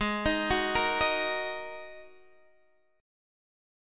Ab7sus2 chord